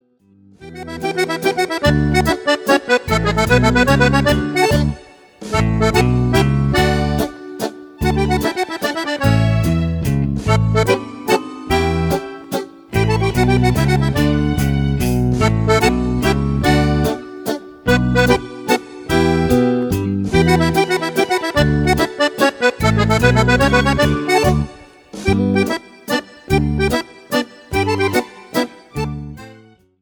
MAZURCA  (3.23)